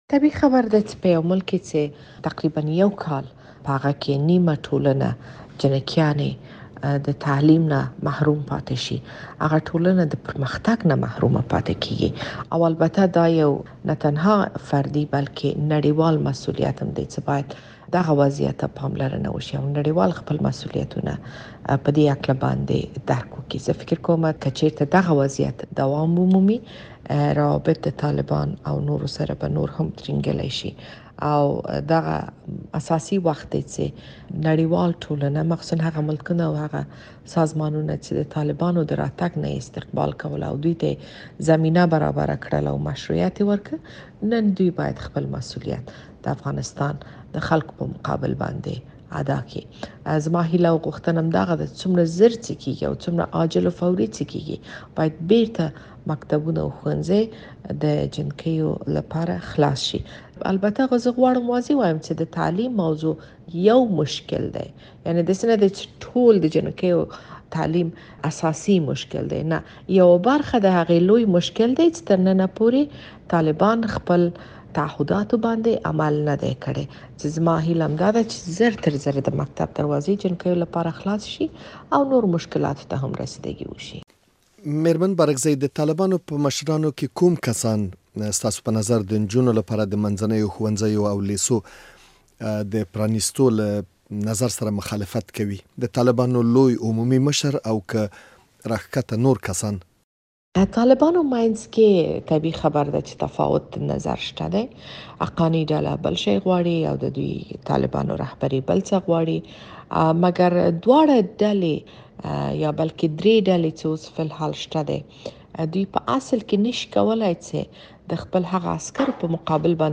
د شکريې بارکزۍ مرکه